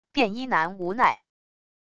便衣男无奈wav音频